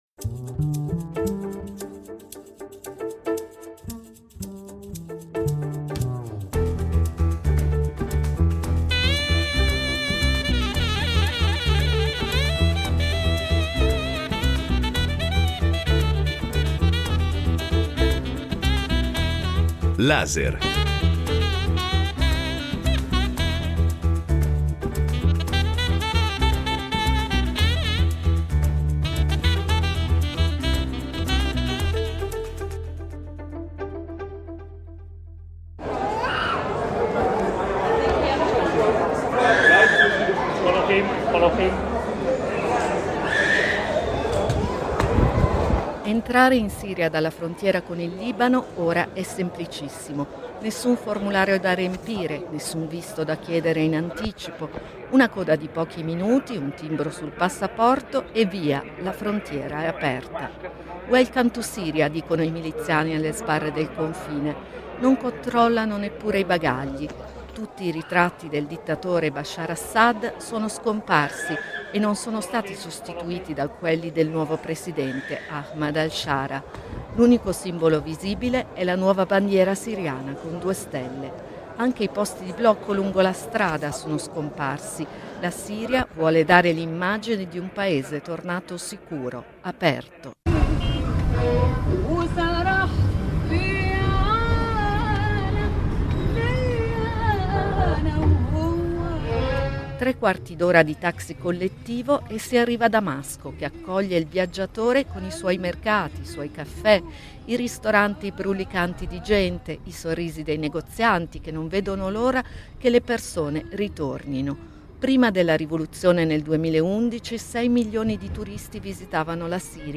Un reportage in viaggio tra Damasco e Aleppo